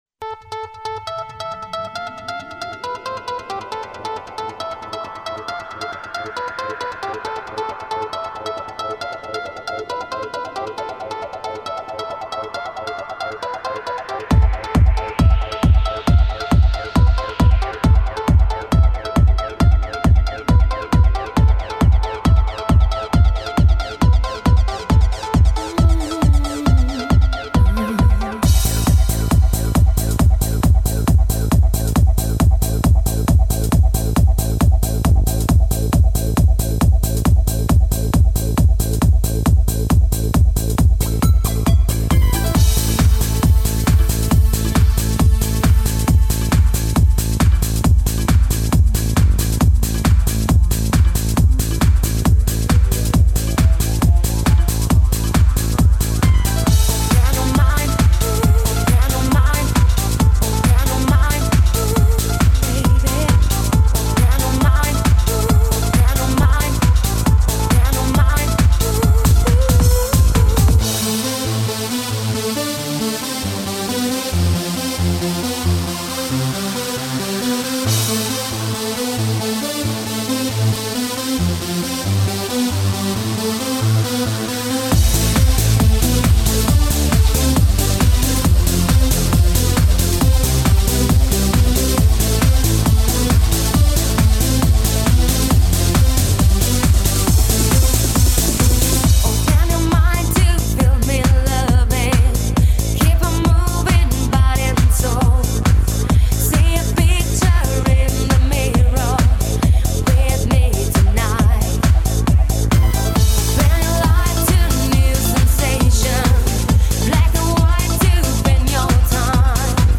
Genre: Dream.